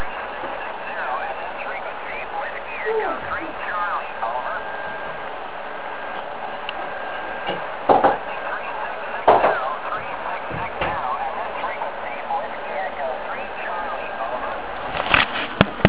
(V dalším jsou záznamy z tohoto závodu)